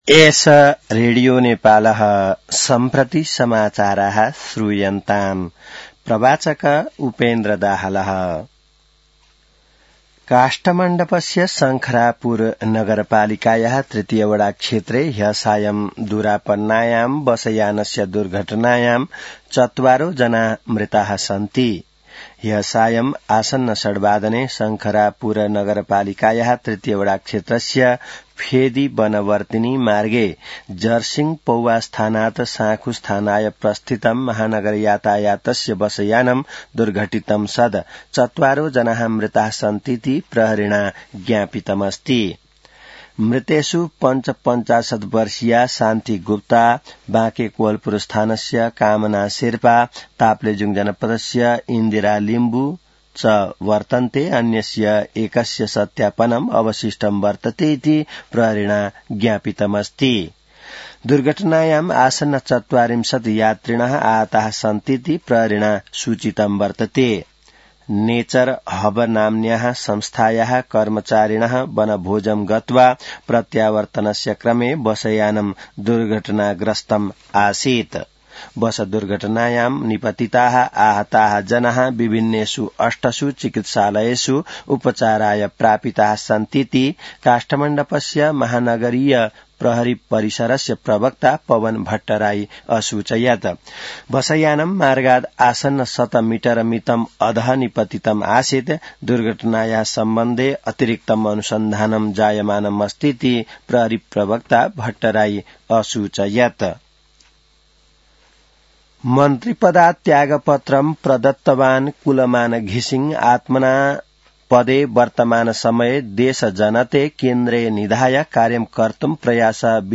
संस्कृत समाचार : २४ पुष , २०८२